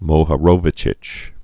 (mōhə-rōvə-chĭch)